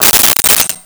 Silverware Movement 03
Silverware Movement 03.wav